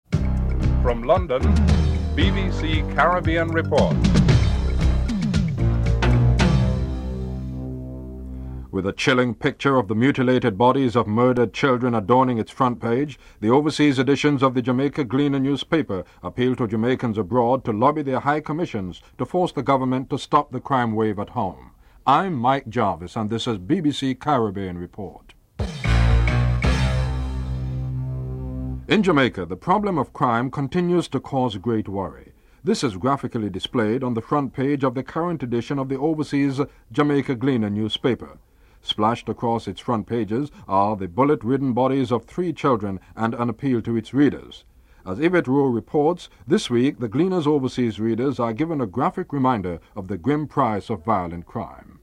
The British Broadcasting Corporation
1. Headlines (00:00-00:34)